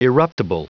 Prononciation du mot eruptible en anglais (fichier audio)
Prononciation du mot : eruptible